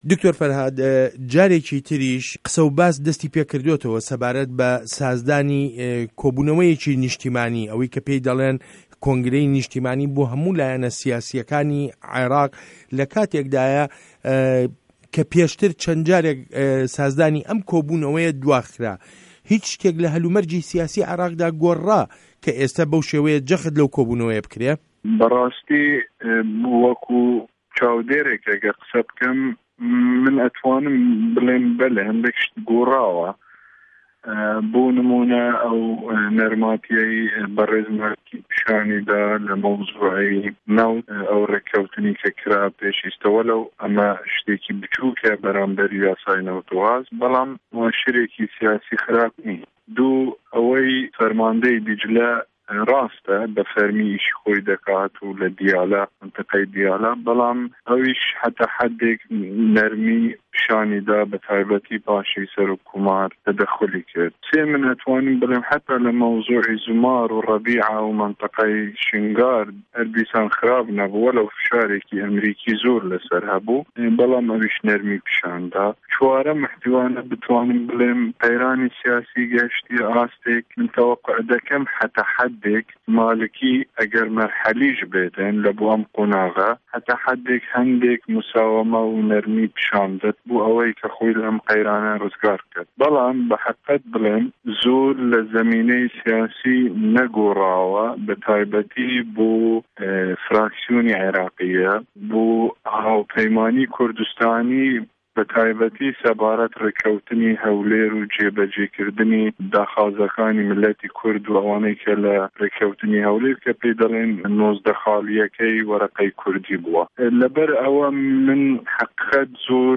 وتووێژ له‌گه‌ڵ دکتۆر فه‌رهاد ئه‌تروشی